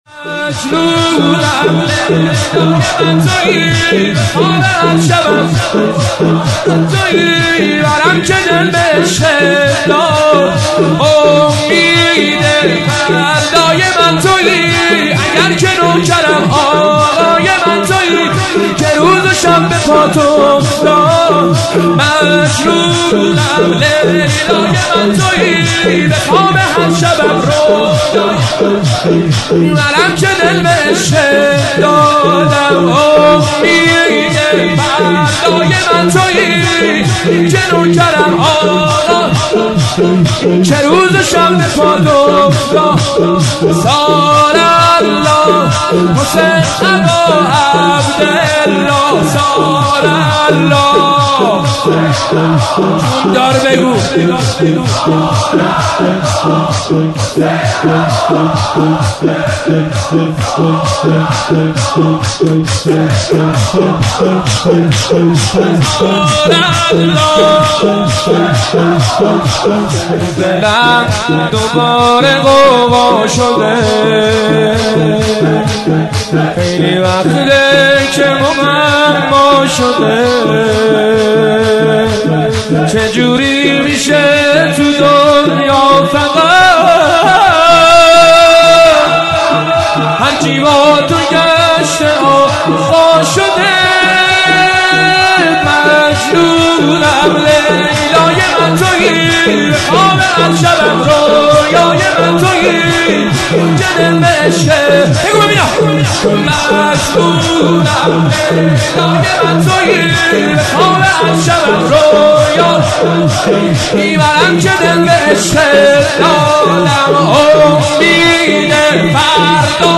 مناسبت : وفات حضرت ام‌البنین سلام‌الله‌علیها
قالب : شور